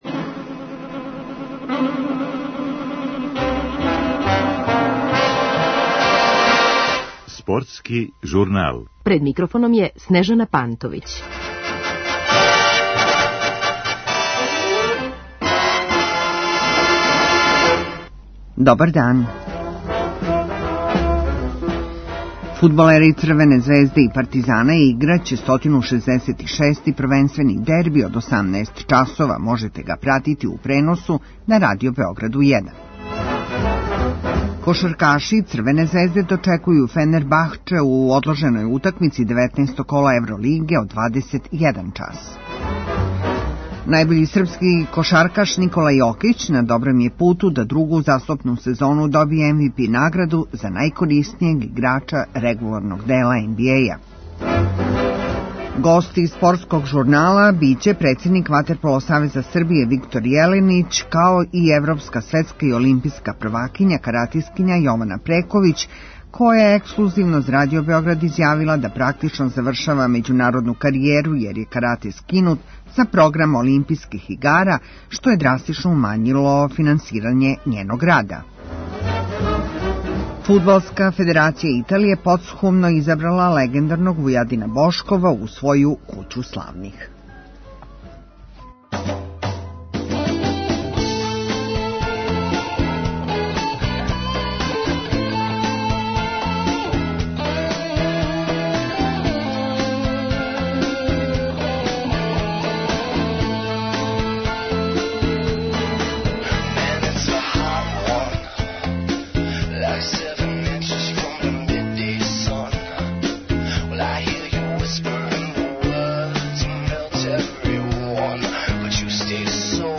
Гошћа Спортског журнала је каратискиња, европска, светска и олимпијска шампионка Јована Прековић, која открива да ли завршава каријеру након сазнања да је карати скинут са програма Летњих олимпијских игара